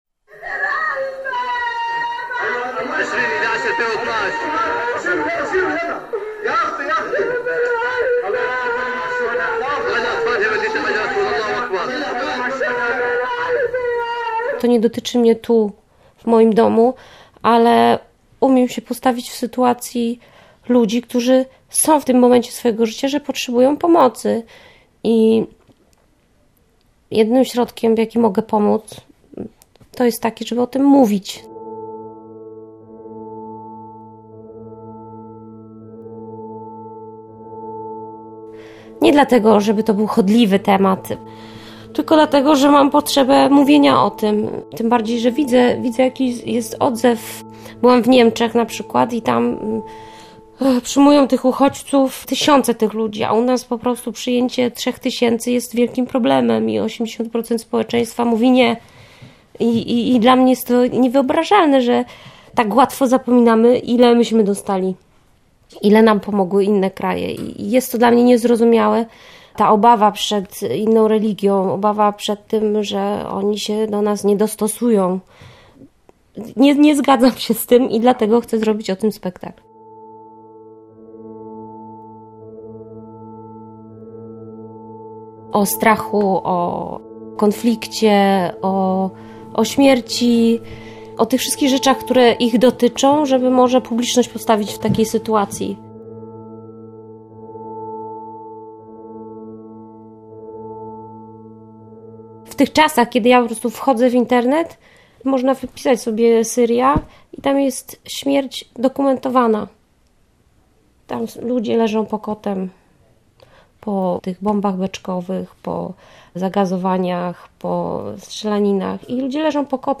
audycje dokument reportaż sztuka